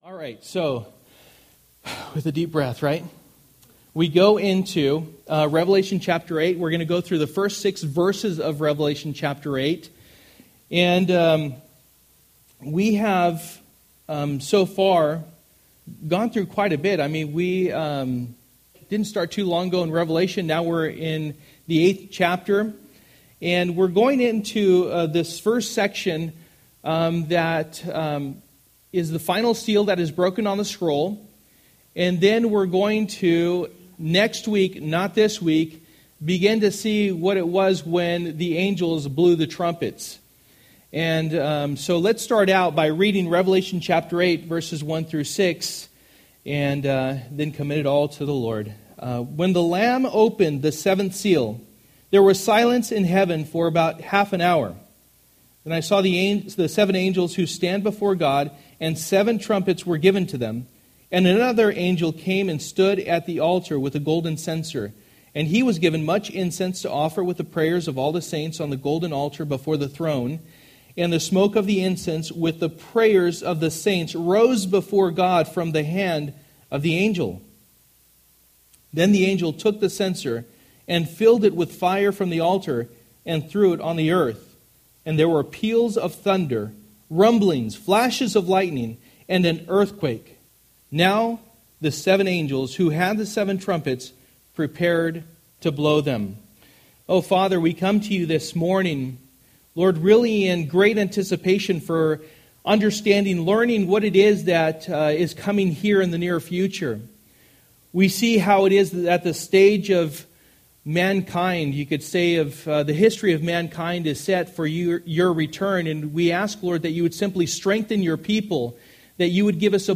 Passage: Revelation 8:1-6 Service: Sunday Morning